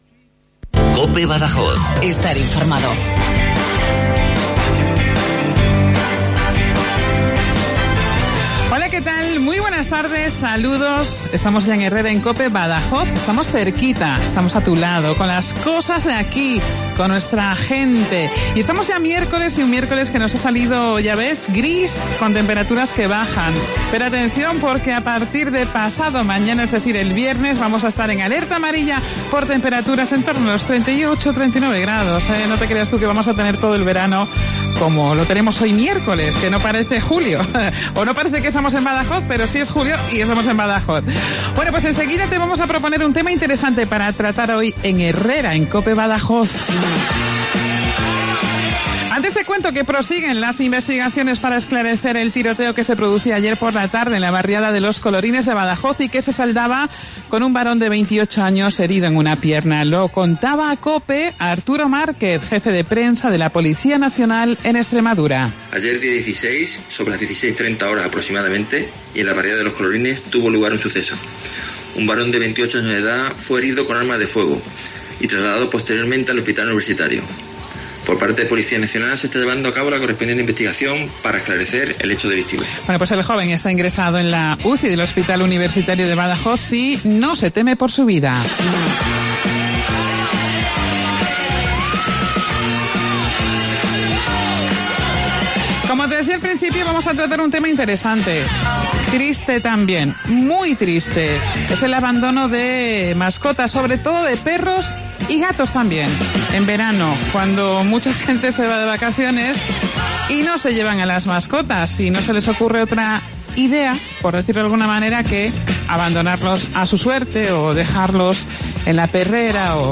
Hoy en Herrera en COPE Badajoz hemos hablado con SOS PERRERA BADAJOZ, con una de sus voluntarias